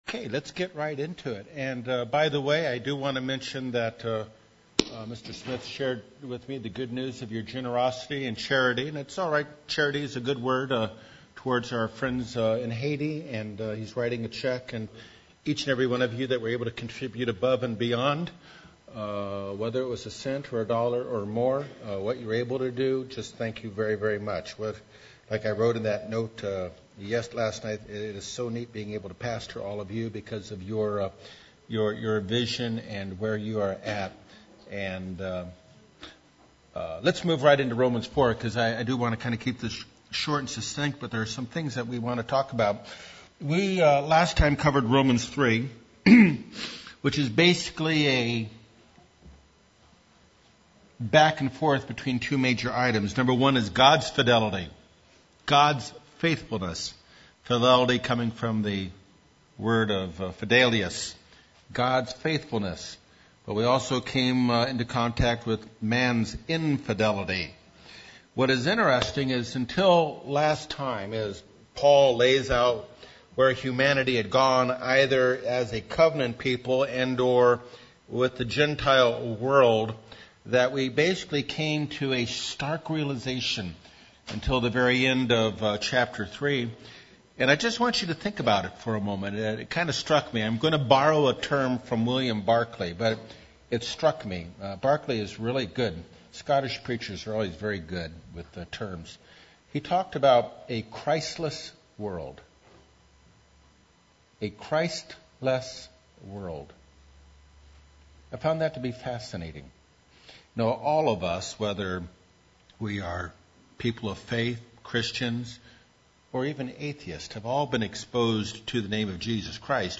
Ongoing Bible study of the book of Romans. God desires simple trust; complete yielding to His Word and He loves us, even when we don't deserve it.